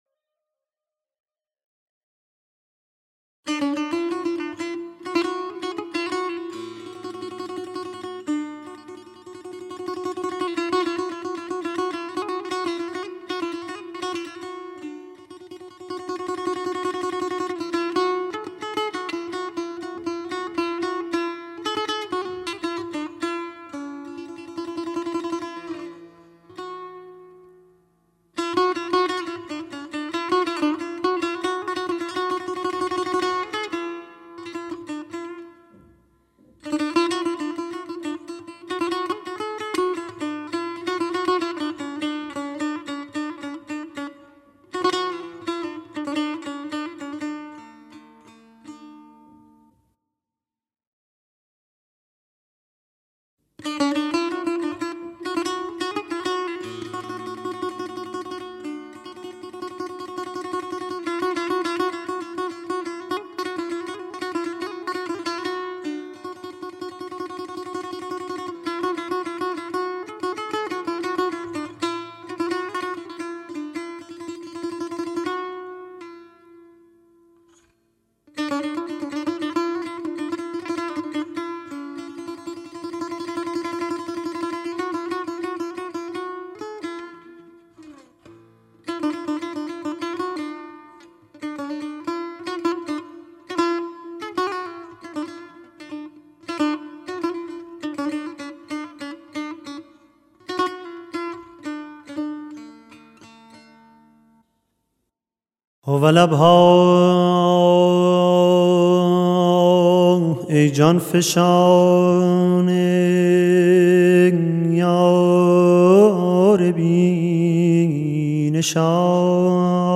قند پارسی لحن فارسی